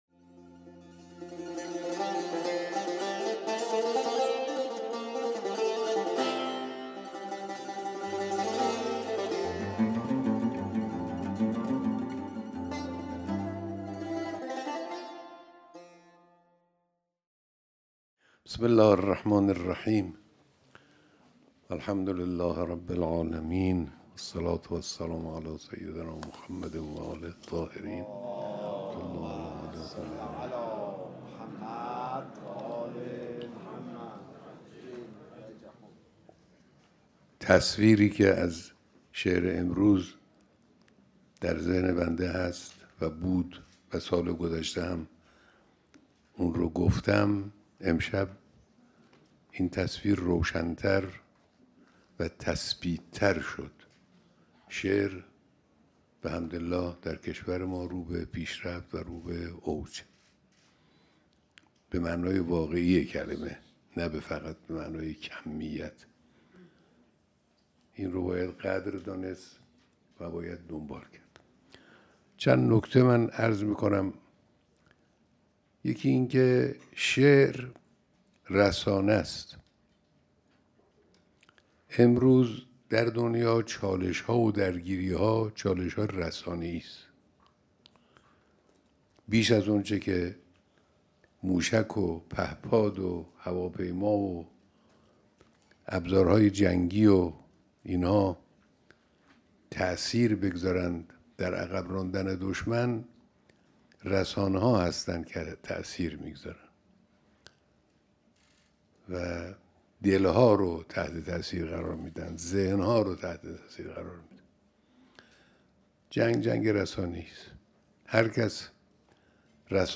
بیانات در دیدار شاعران و اهالی فرهنگ و ادب فارسی